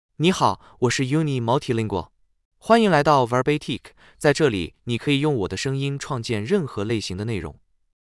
Yunyi MultilingualMale Chinese AI voice
Yunyi Multilingual is a male AI voice for Chinese (Mandarin, Simplified).
Voice sample
Listen to Yunyi Multilingual's male Chinese voice.
Male